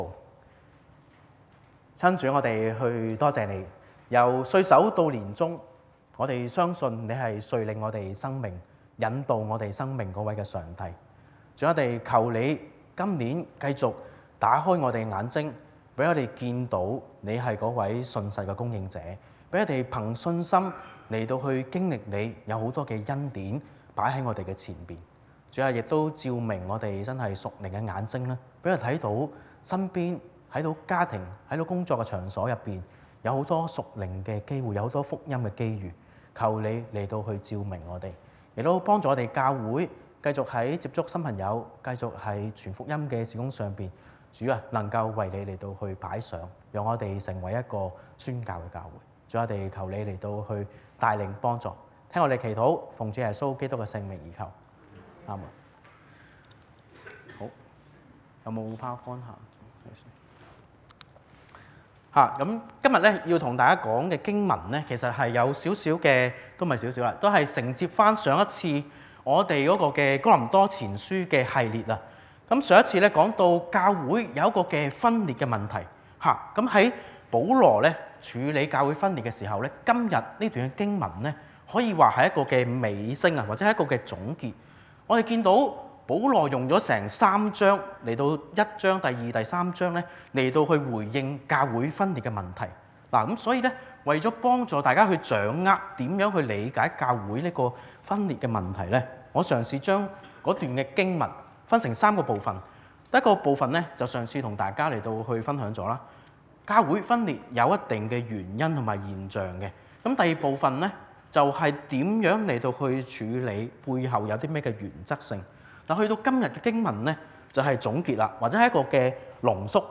Sermons | New Covenant Alliance Church (NCAC) 基約宣道會 - Part 7